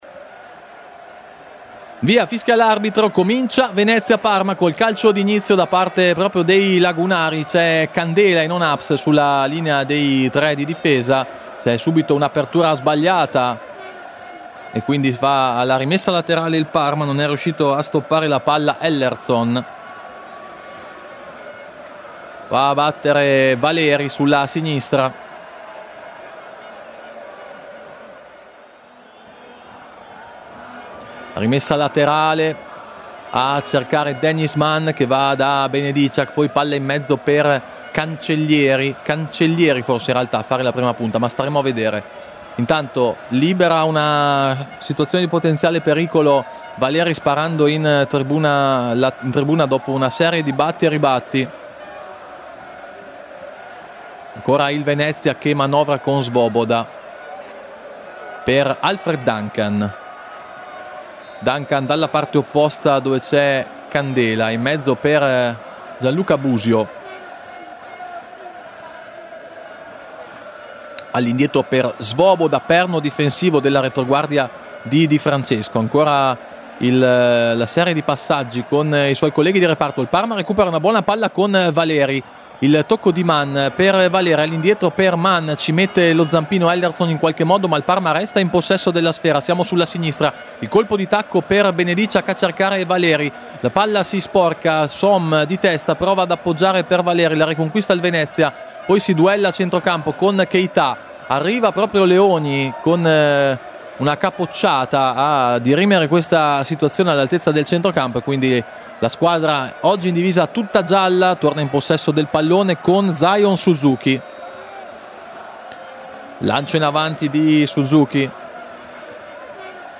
Radiocronache Parma Calcio Venezia - Parma 1° tempo - 9 novembre 2024 Nov 09 2024 | 00:47:25 1x 00:00 / 00:47:25 Subscribe Share RSS Feed Share Link Embed